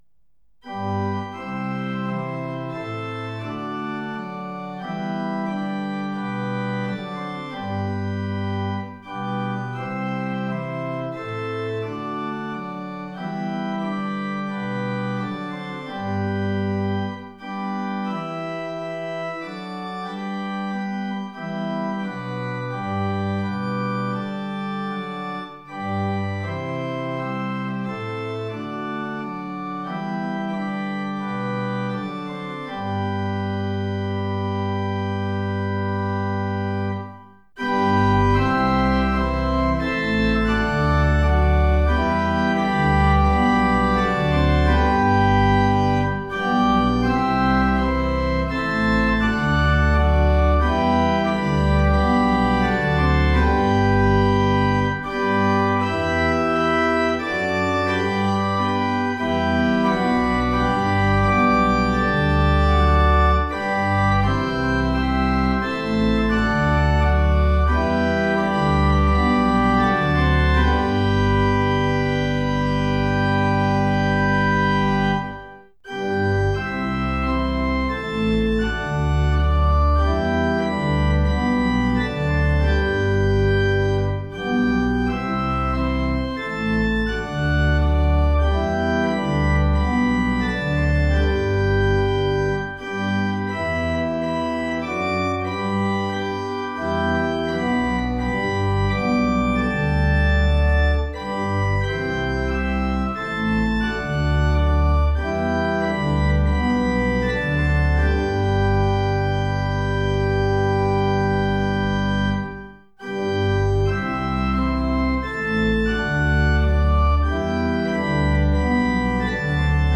Audio Recordings (Organ)
WS743-midquality-mono.mp3